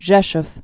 (zhĕshf)